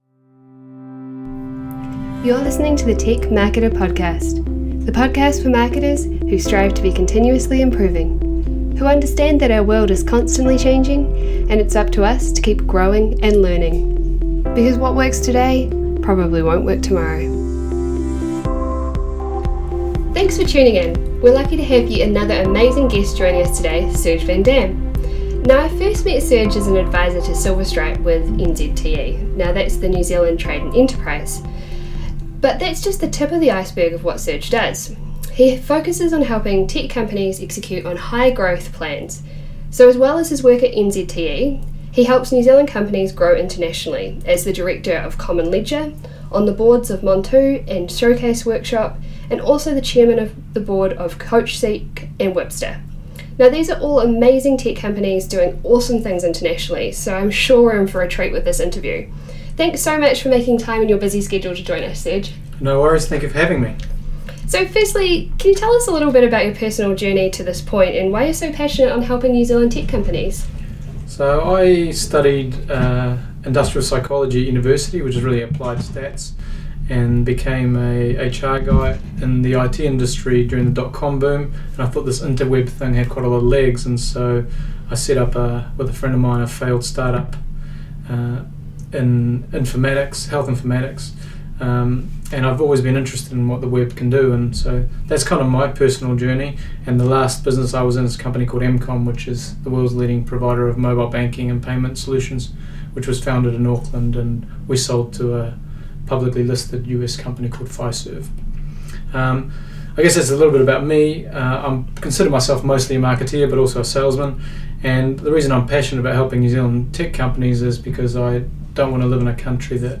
In this interview we discuss: The importance of collaboration across tech companies Openness and transparency in the NZ tech industry Keeping up with the pace of change in marketing How our authentic Kiwi culture helps content marketing Creating content marketing sub-brands And marketing accountability leading to more marketers at board level